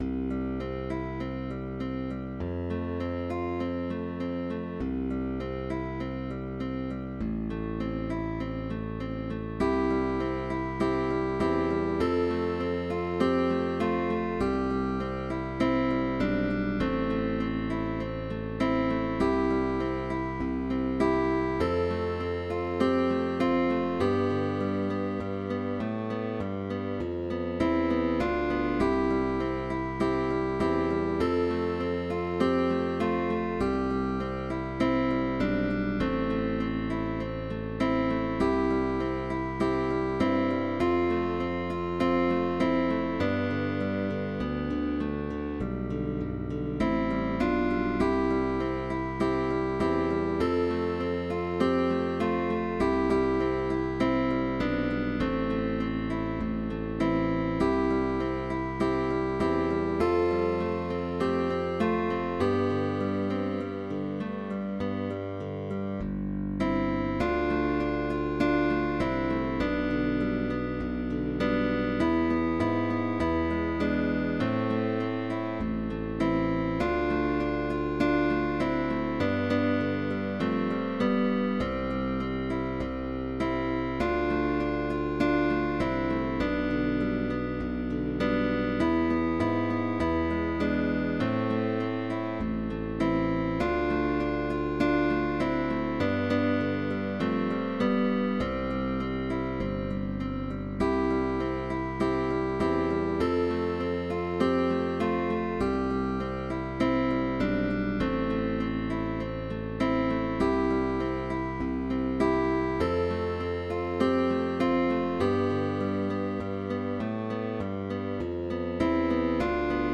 Tags: Pop Songs